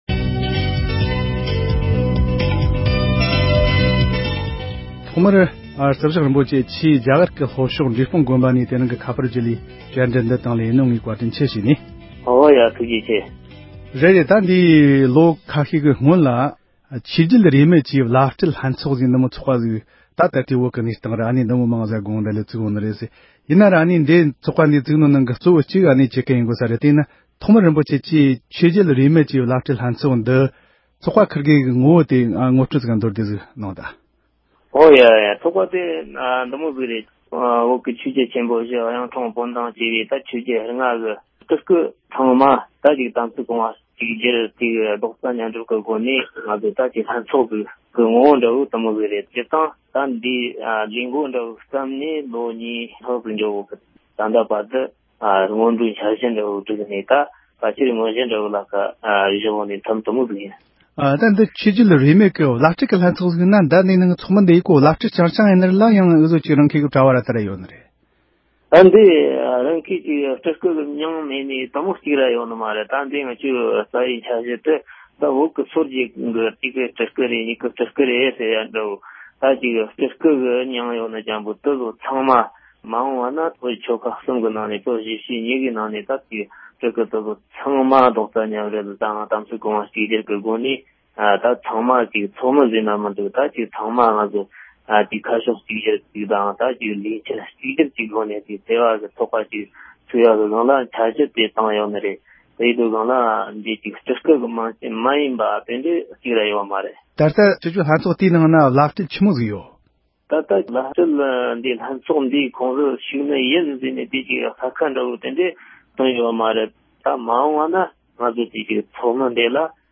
བཀའ་འདྲི་ཞུས་པར་གསན་རོགས་ཞུ༎